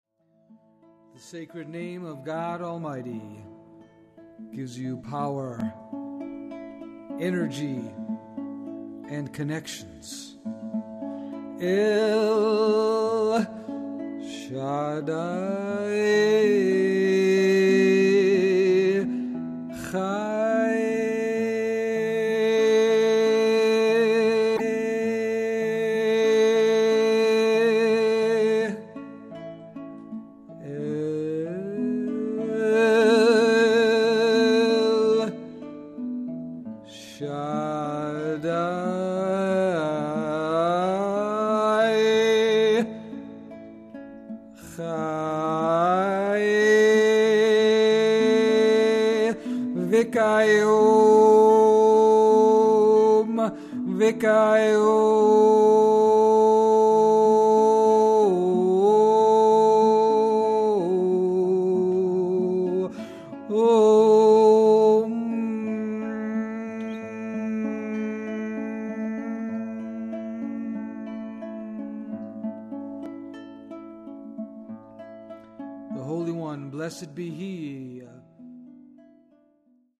durch heilige Klänge mit Meistern der fünften Dimension.
mit musikalischer Begleitung